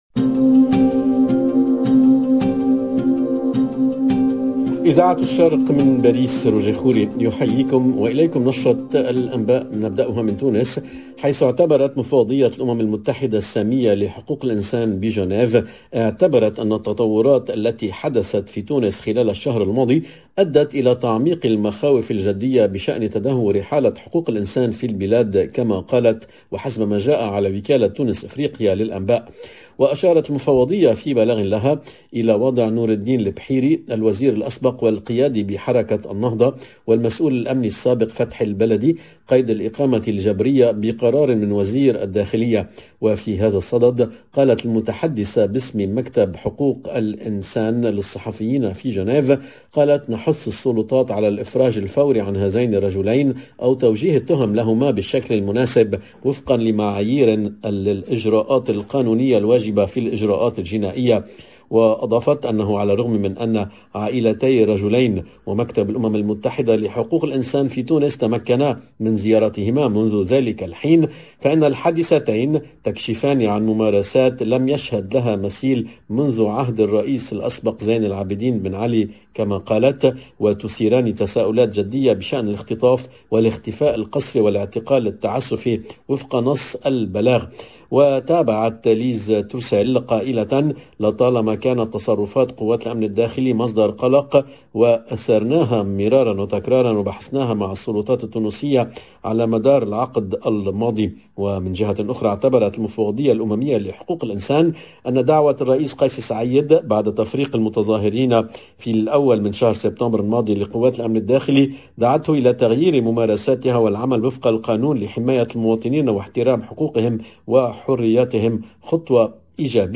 LE JOURNAL DE MIDI 30 EN LANGUE ARABE DU 12/01/2022